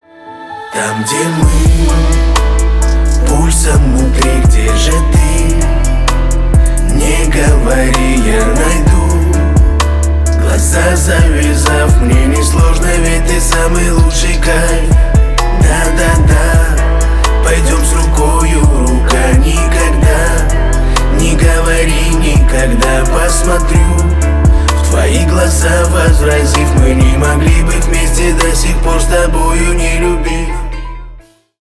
Рэп и Хип Хоп
грустные